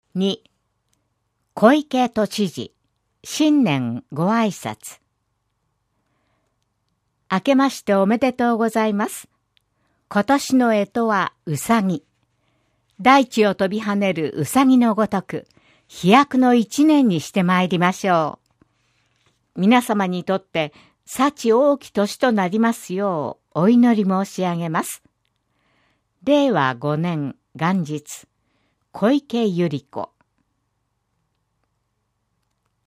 「広報東京都音声版」は、視覚に障害のある方を対象に「広報東京都」の記事を再編集し、音声にしたものです。
小池都知事 新年ごあいさつ（MP3：257KB） 32秒